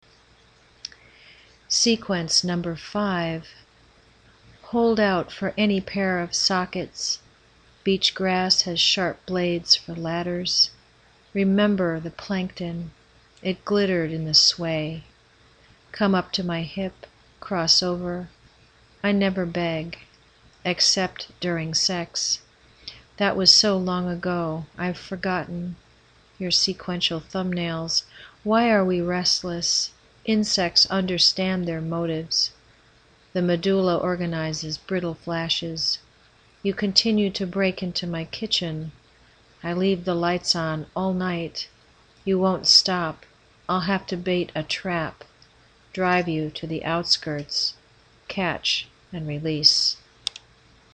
I am glad I came across your site and poem -I enjoyed hearing you read it.